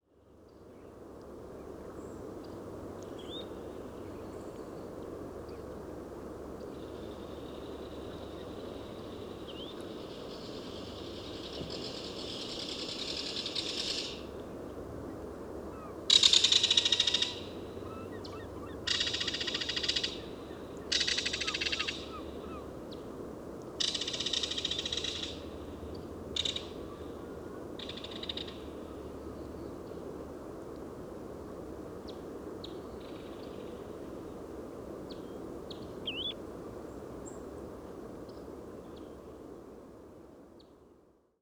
Martin-pêcheur d’Amérique – Megaceryle alcyon
Cri ‘Rattle’ Un Martin-pêcheur d’Amérique arrive tout prêt et se pose brièvement sur une branche avant de repartir sur son chemin. Parc nature de Pointe-aux-Outardes, QC, 49°02’32.0″N 68°27’17.7″W. 7 septembre 2018. 8h10.